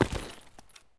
0335ec69c6 Divergent / mods / Soundscape Overhaul / gamedata / sounds / material / human / step / default1.ogg 36 KiB (Stored with Git LFS) Raw History